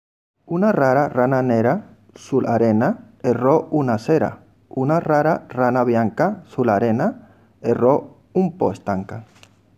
But today I propose to you a few Italian tongue-twisters read by some foreigners who live in Oslo, because I would like you to listen to their different accents and underline the beauty of each.
because we know that both letters in Spanish have the same sound and he adds the “e” to the word “stanca“